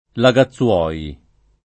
[ la g a ZZU0 i ]